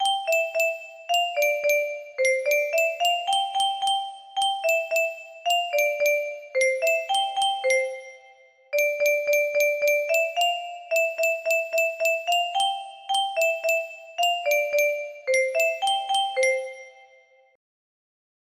Sol Mi Mi music box melody